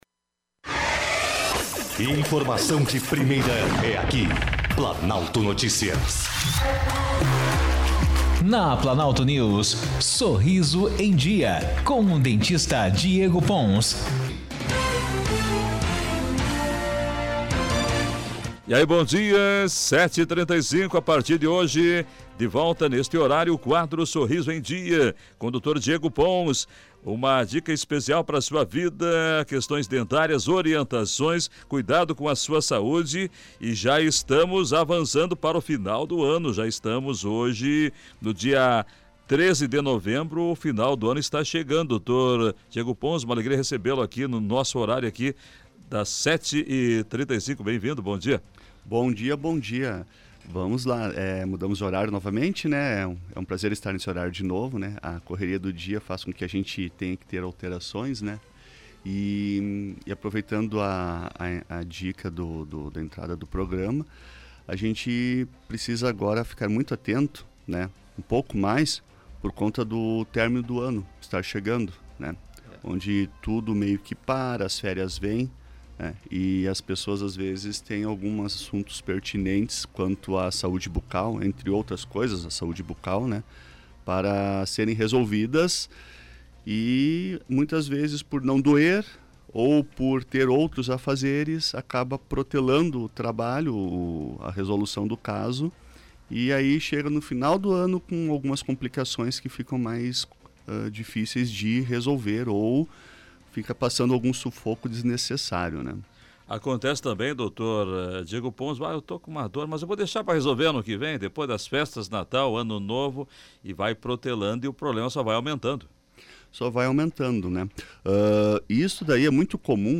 O áudio completo da entrevista pode ser ouvido abaixo: